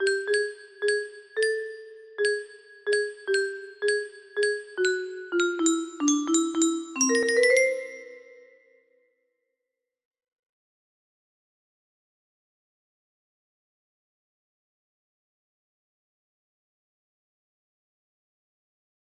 massive frog guy music box melody